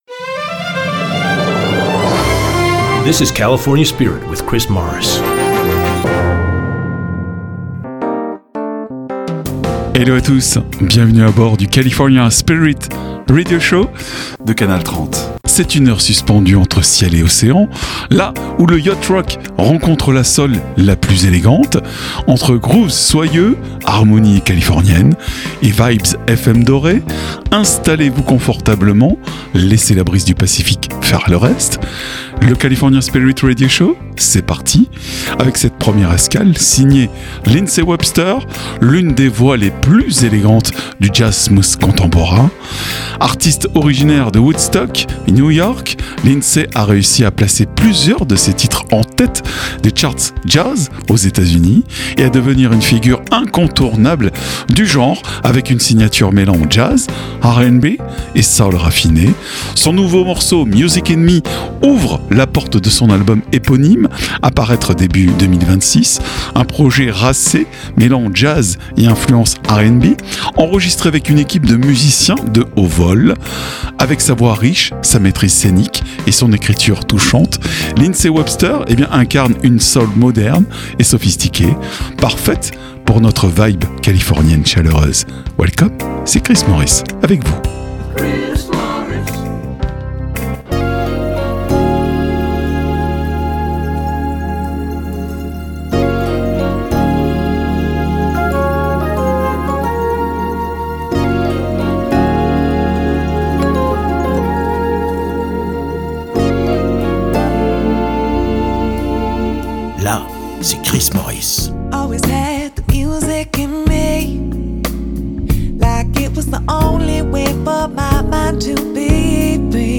Le California Spirit est un concept unique inspiré des radios US , avec des PowersPlays (nouveautés) et ExtraGold (Oldies).
C’est un format musique californienne (Allant du Classic Rock en passant par le Folk, Jazz Rock, Smooth jazz) le tout avec un habillage visuel très 70’s et un habillage sonore Made in America. Tout cela forme l’AOR music (Album Oriented rock) qui naissait au milieu des années 70 par des djays américains.